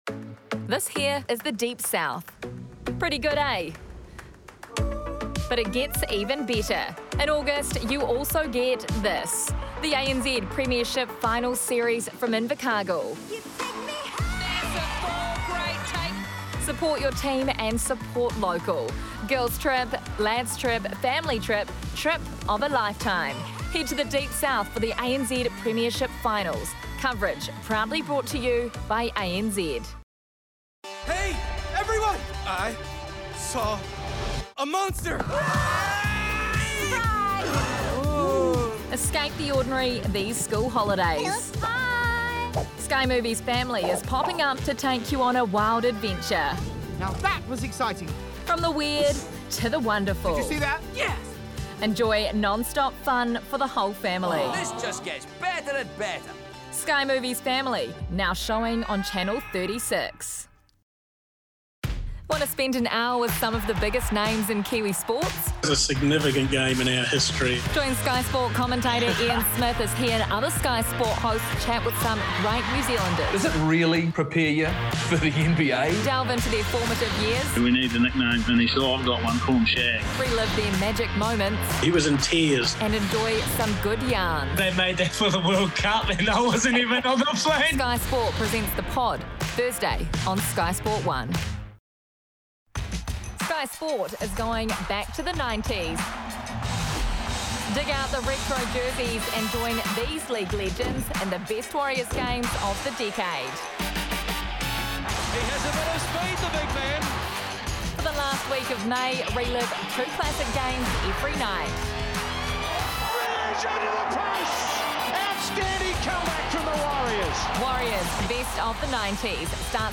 Demo
Adult
Has Own Studio
new zealand | natural
COMMERCIAL 💸